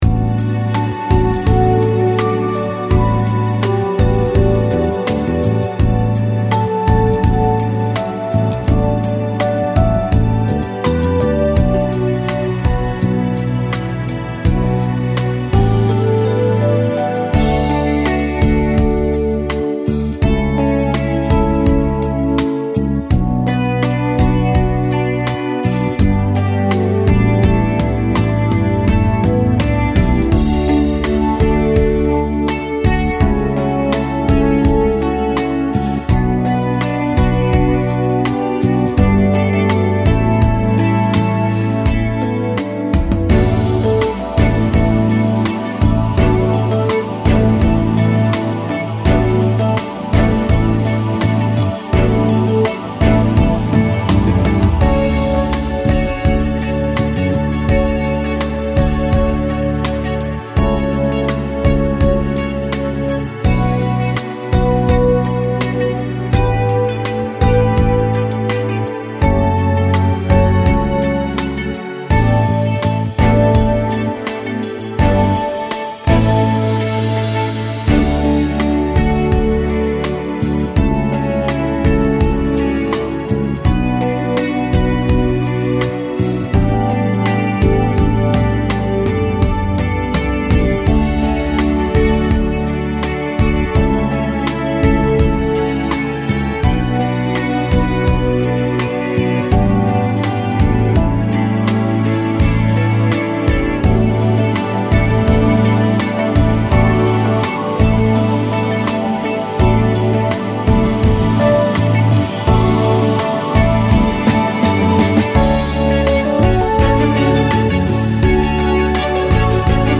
nostalgique - calme - guitare electrique - air